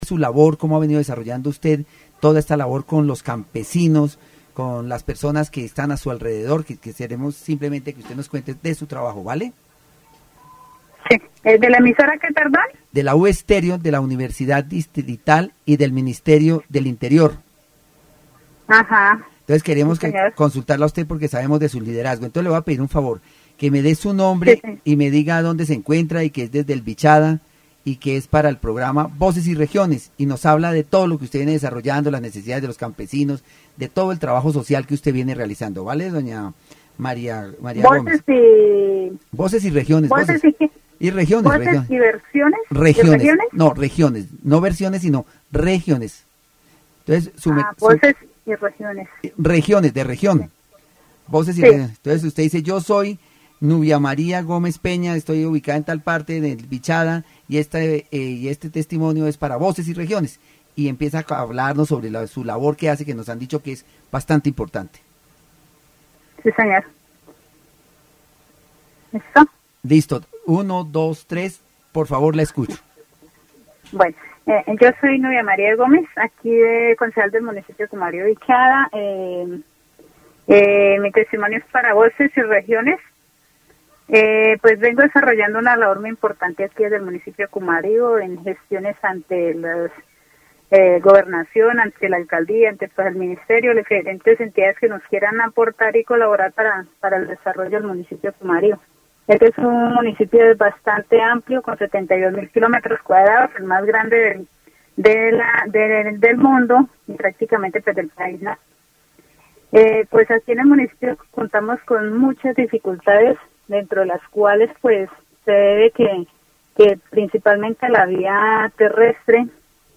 The radio program "Voices and Regions" featured an interview with Nubia María Gómez Peña, councilwoman of Cumaribo, Vichada. The discussion focused on the challenges faced by peasants, particularly the lack of road infrastructure, difficulty accessing agricultural and livestock resources, and the need for land titling for small farmers.